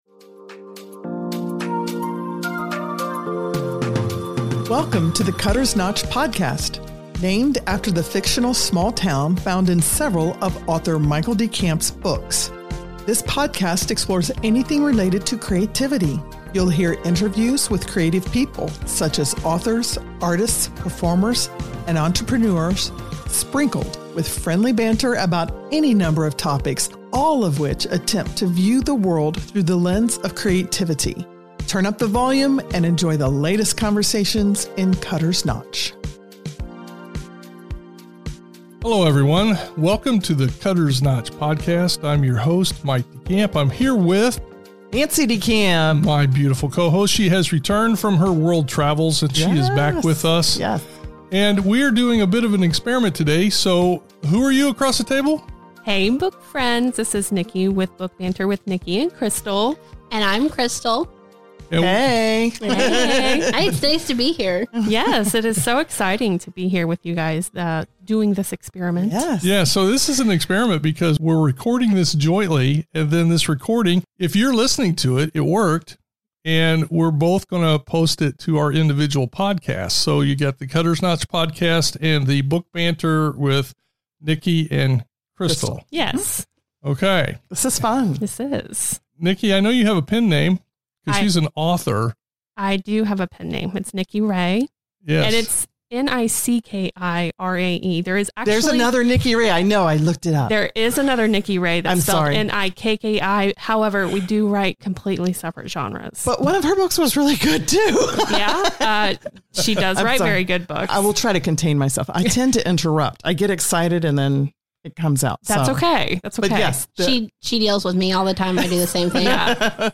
Recorded in the Cutters Notch studio, the plan is for it to be dually released by both podcasts. Taking turns in the hosting chairs, we talk books, writing methods, and our usua...